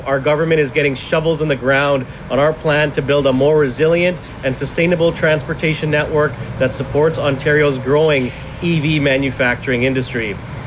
They gathered at the westbound Trenton ONroute to announce that fast chargers had been installed at all ONroutes, ahead of the summer tourist season.